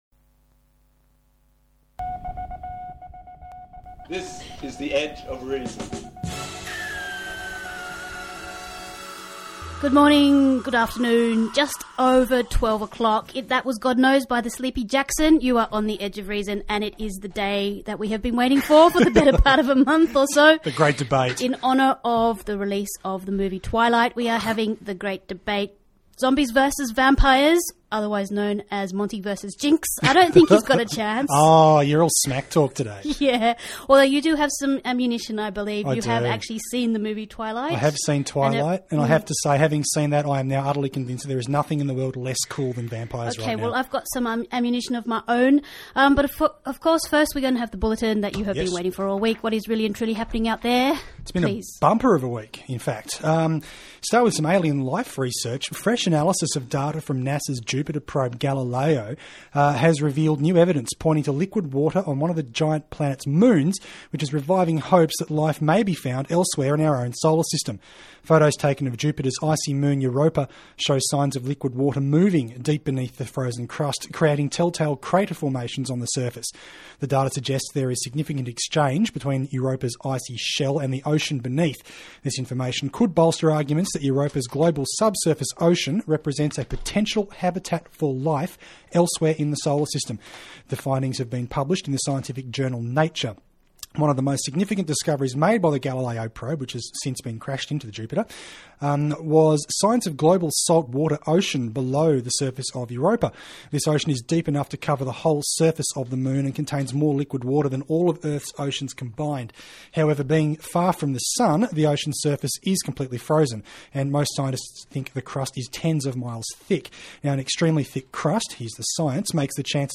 The show provided news and information about psi and related paranormal areas in a news magazine format.